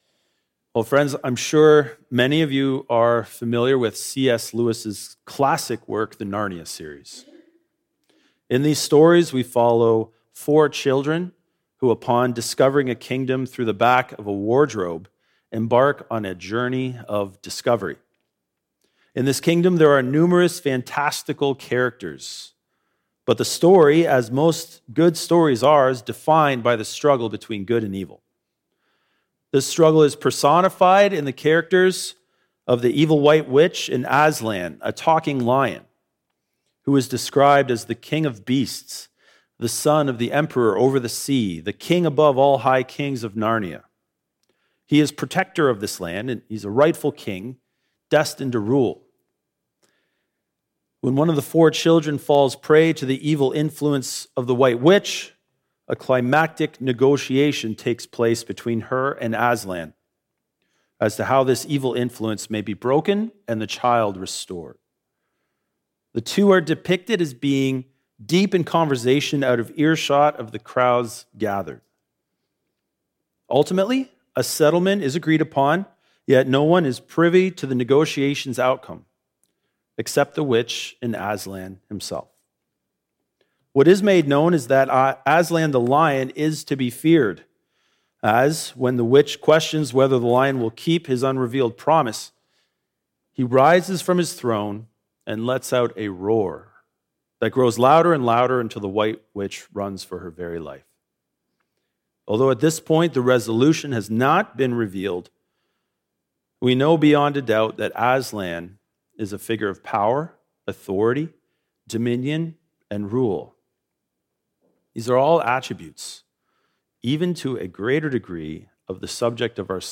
A sermon on Psalm 76